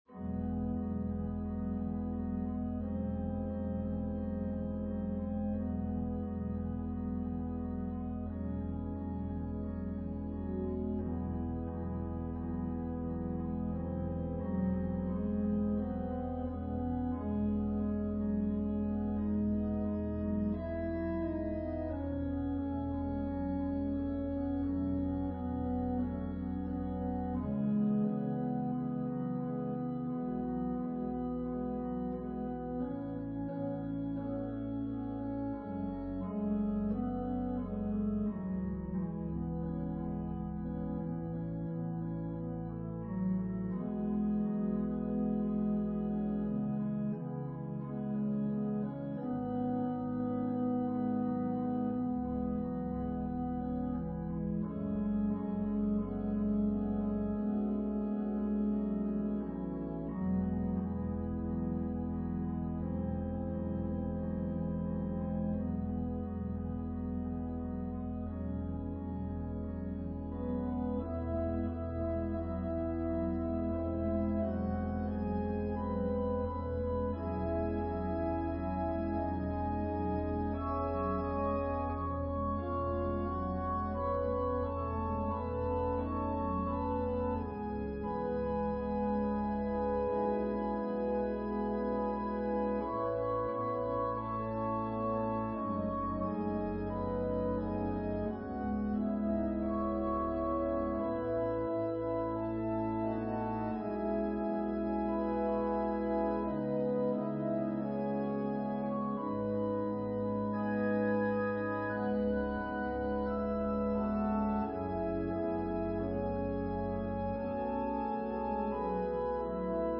An organ solo version of Mack Wilberg's arrangement as performed by the Tabernacle Choir on Temple Square.
Voicing/Instrumentation: Organ/Organ Accompaniment We also have other 6 arrangements of " Simple Gifts ".